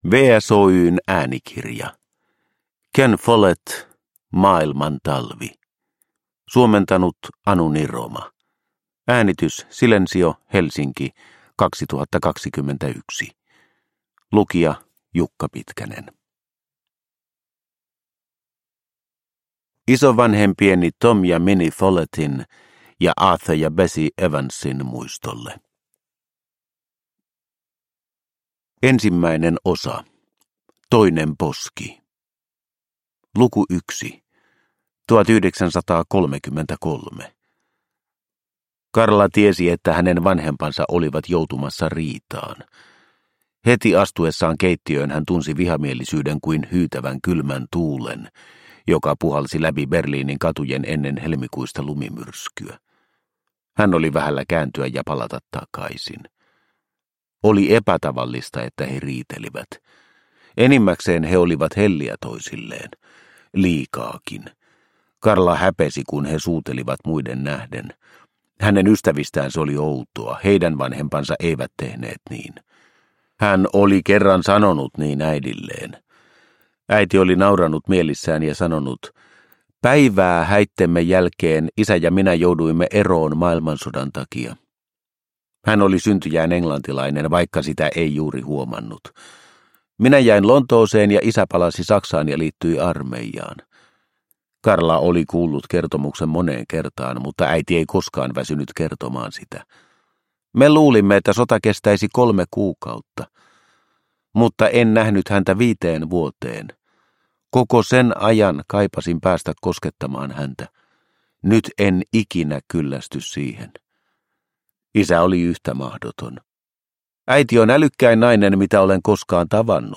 Maailman talvi – Ljudbok – Laddas ner